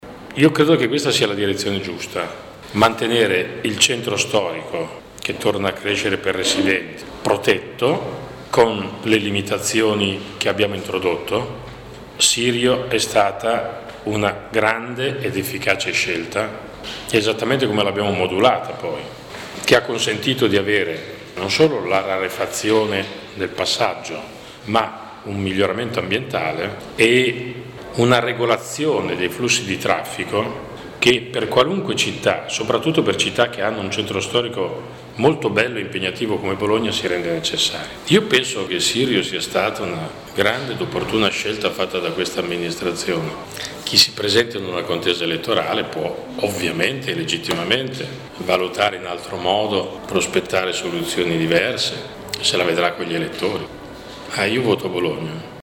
Ascolta il sindaco Sergio Cofferati